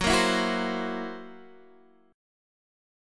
Gb7b5 chord